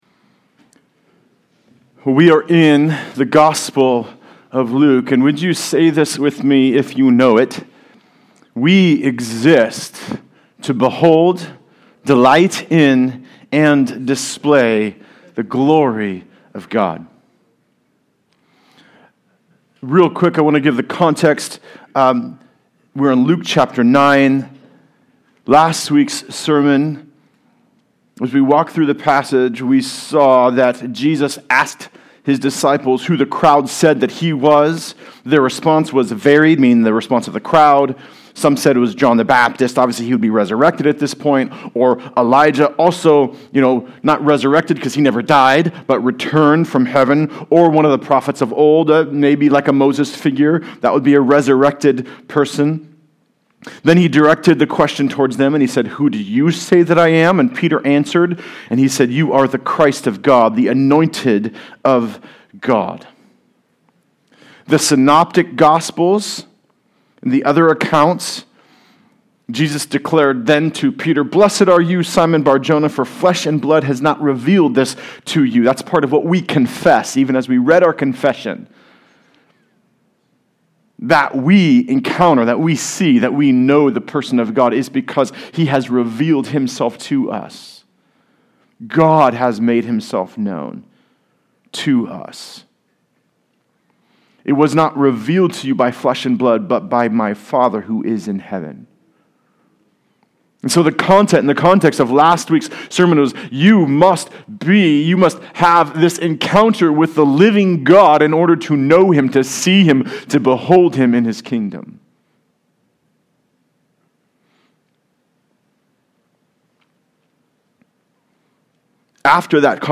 Good News for All People Passage: Luke 9: 28-36 Service Type: Sunday Service Related « Who Do You Say I Am?